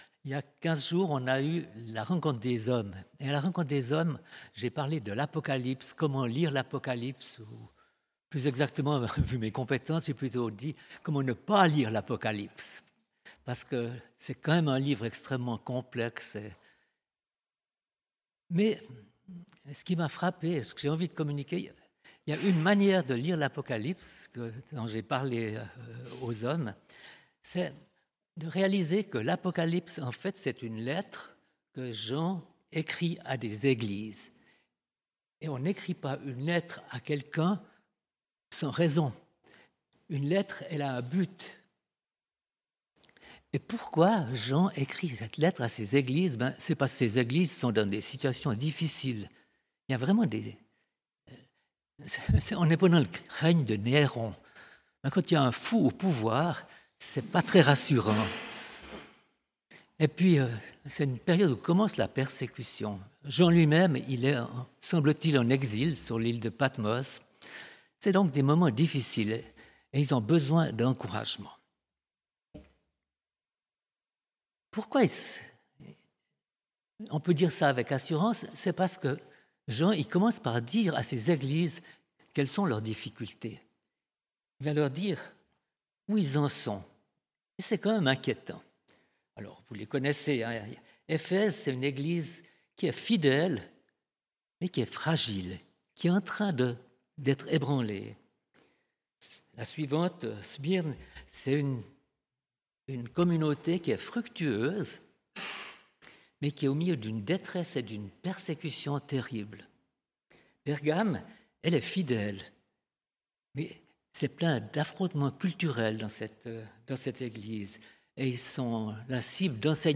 Type De Rencontre: Culte